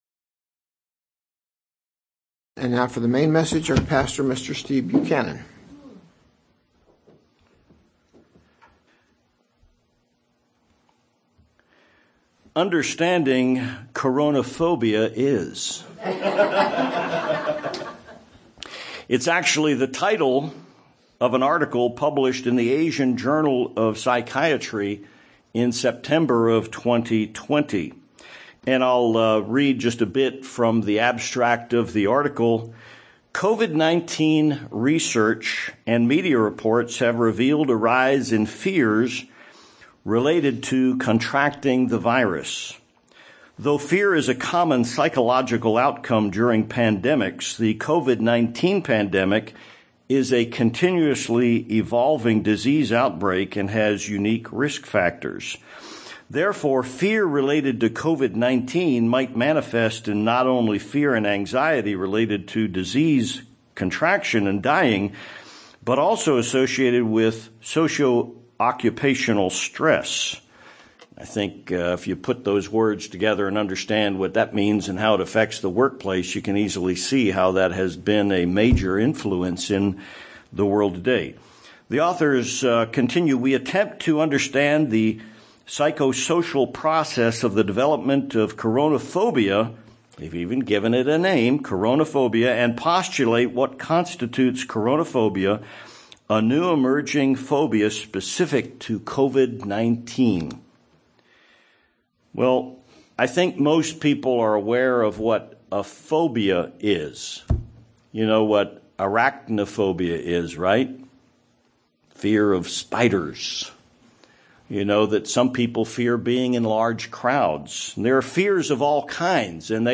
Psychologists have identified a new fear in the past two years -- "Coronaphobia." This sermon identifies a few simple Biblical pathways to conquering fear.